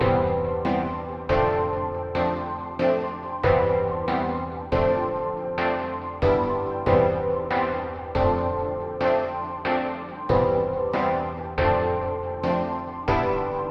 快乐陷阱钢琴
描述：ap钢琴140bpm
Tag: 140 bpm Trap Loops Piano Loops 2.31 MB wav Key : Unknown